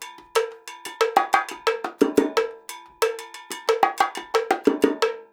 90 BONGO 7.wav